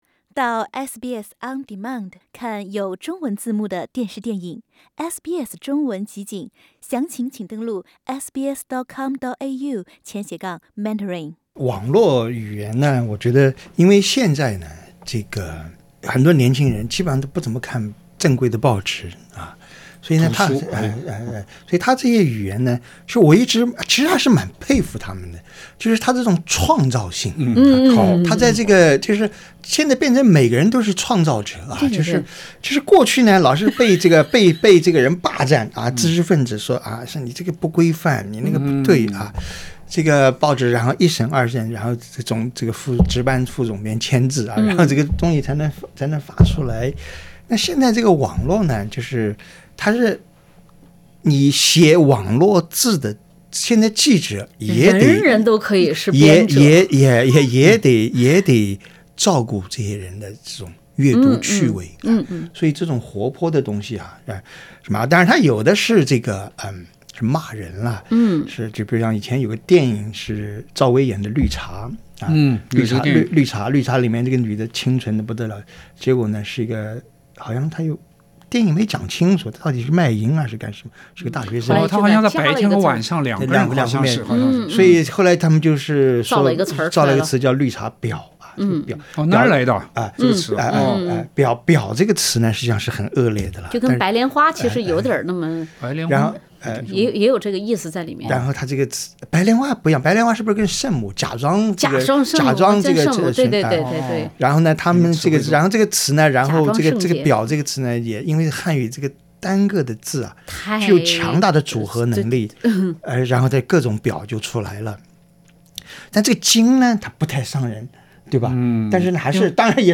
网络热词是自嘲还是轻蔑，是共鸣还是呼喊？苦丁茶三人组各有观点 。